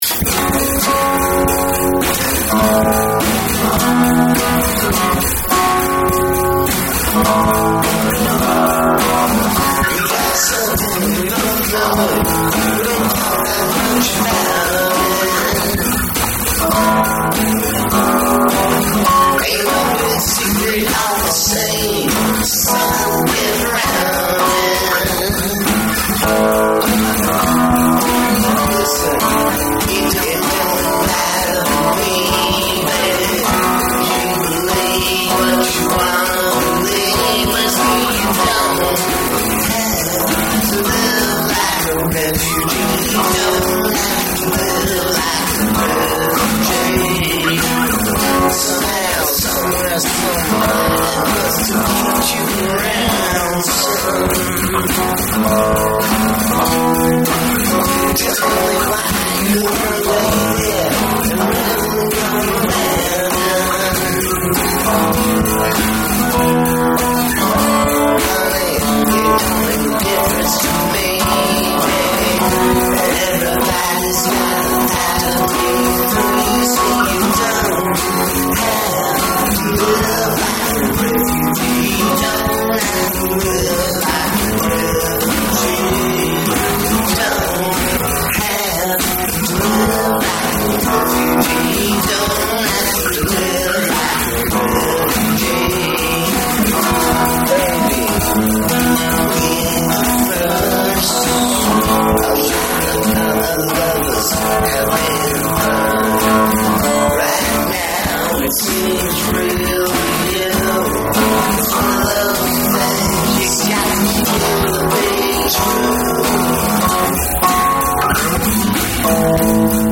though I feel the tempo could be faster.